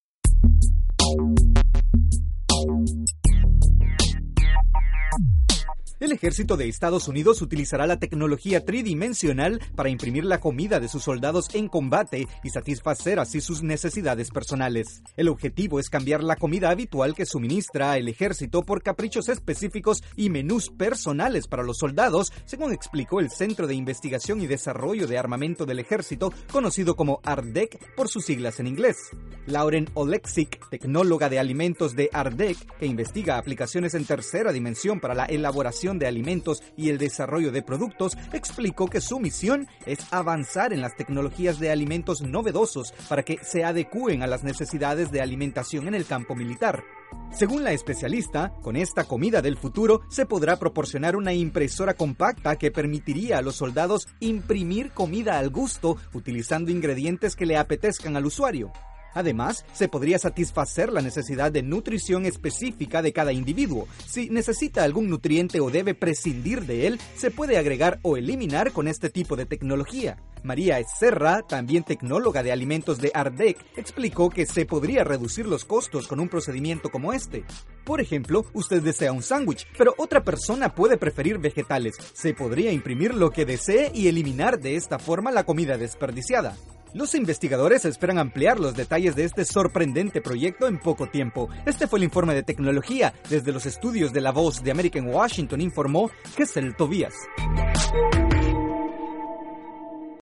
El ejército de Estados Unidos utilizará la impresión tridimensional para la comida de sus soldados en combate, o en sitios remotos. Desde los estudios de la Voz de América en Washington informa